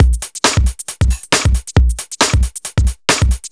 Index of /musicradar/uk-garage-samples/136bpm Lines n Loops/Beats